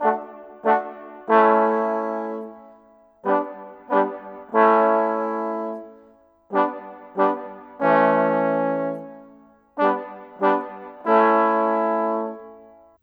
Rock-Pop 06 Brass 01.wav